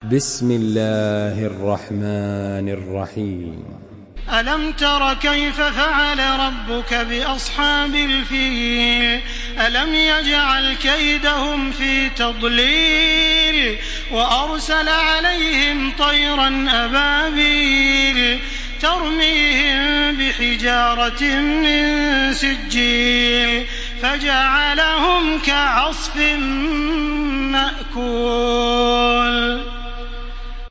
Surah الفيل MP3 by تراويح الحرم المكي 1434 in حفص عن عاصم narration.
مرتل حفص عن عاصم